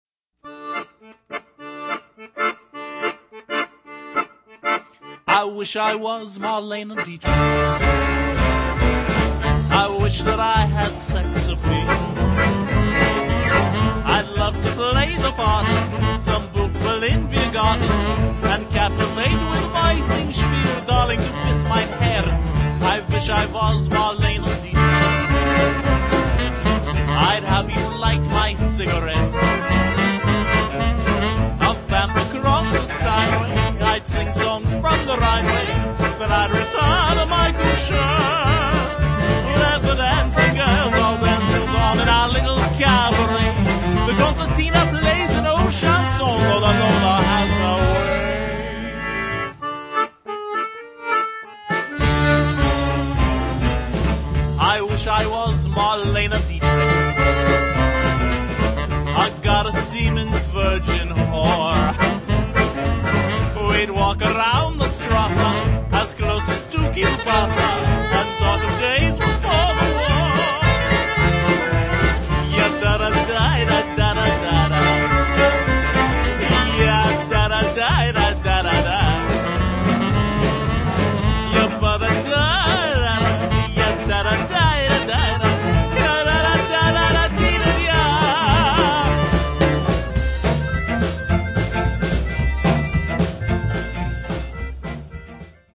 all played with exuberance, skill and a gritty Vagabond edge